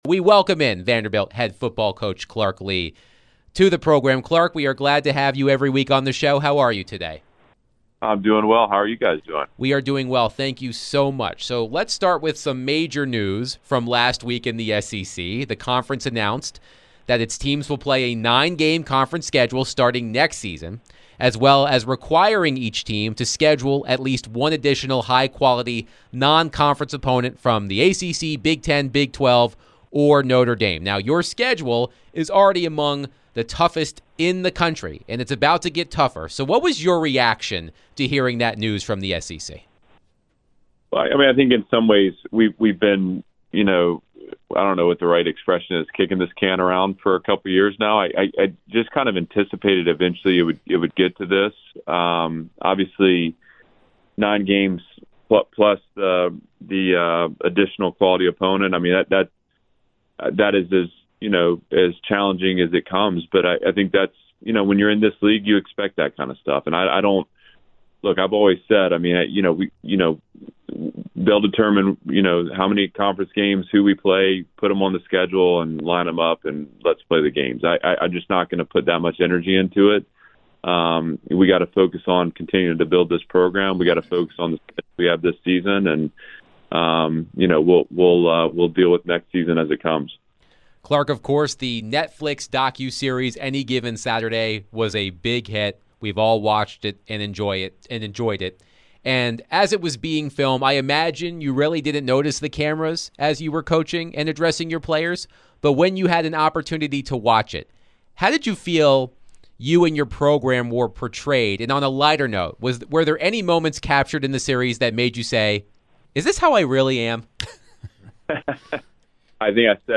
Vanderbilt Football Head Coach Clark Lea joins DVD to discuss Vanderbilt Vs Charleston Southern game this Saturday and more